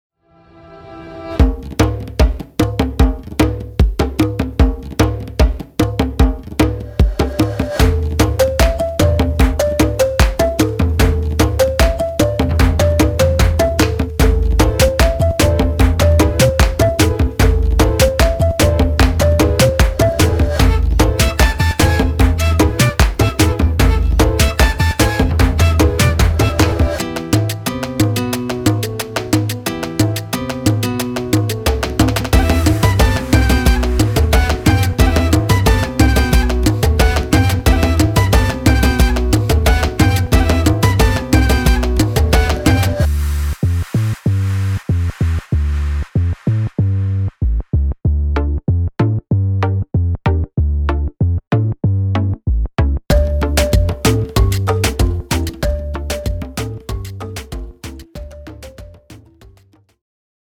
Latin American